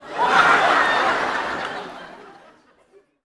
Audience Laughing-07.wav